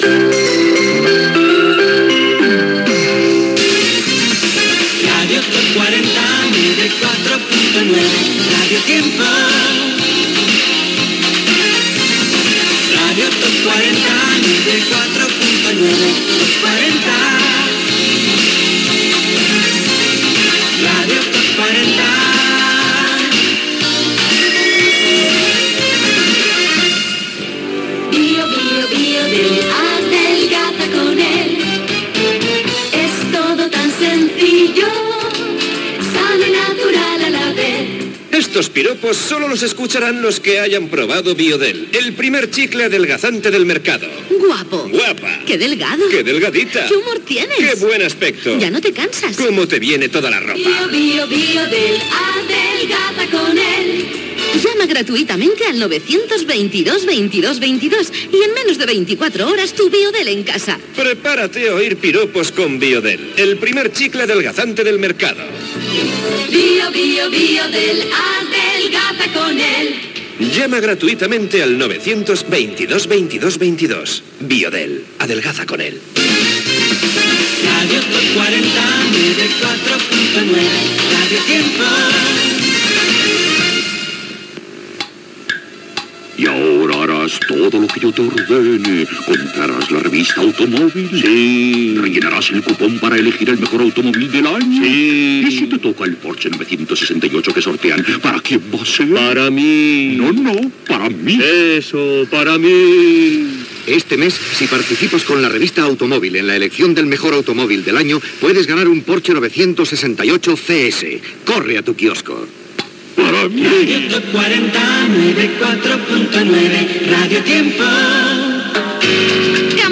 Indicatiu de la ràdio, publicitat, indicatiu, publicitat, indicatiu, publicitat, indicatiu
FM